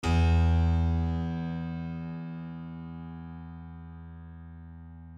piano-sounds-dev
LoudAndProudPiano
e1.mp3